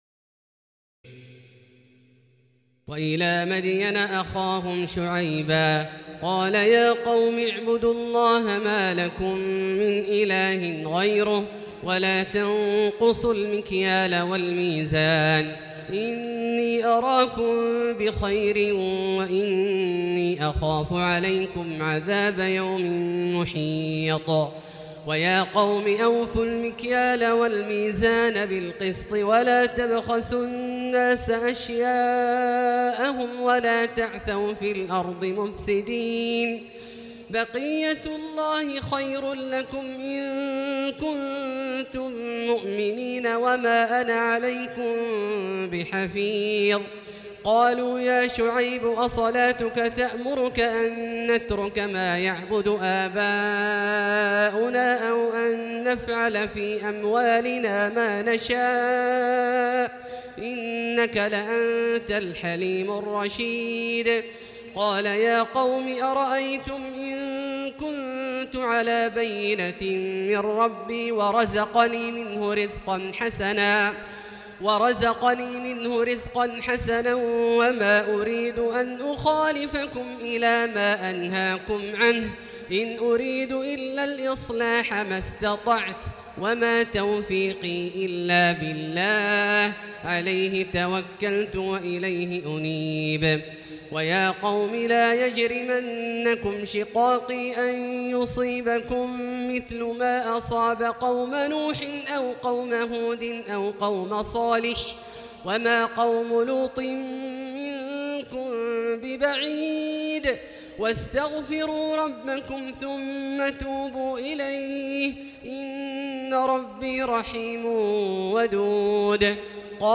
تراويح الليلة الحادية عشر رمضان 1439هـ من سورتي هود (84-123) و يوسف (1-57) Taraweeh 11 st night Ramadan 1439H from Surah Hud and Yusuf > تراويح الحرم المكي عام 1439 🕋 > التراويح - تلاوات الحرمين